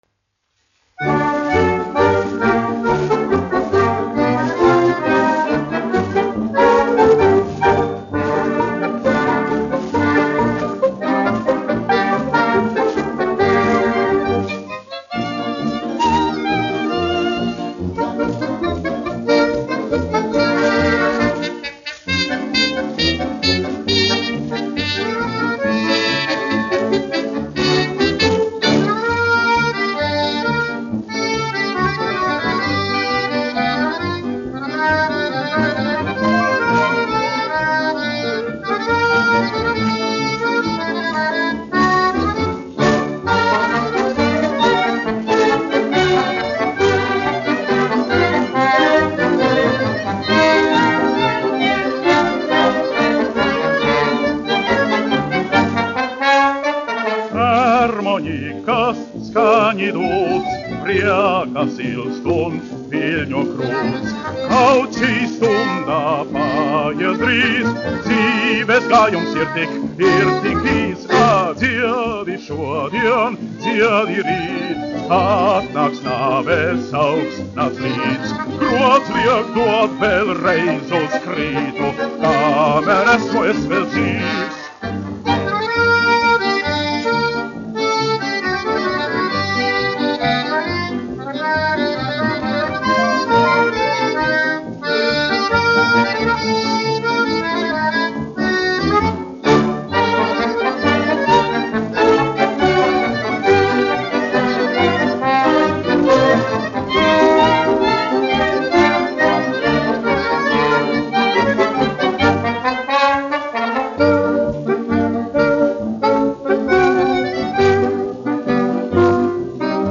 1 skpl. : analogs, 78 apgr/min, mono ; 25 cm
Fokstroti
Populārā mūzika
Skaņuplate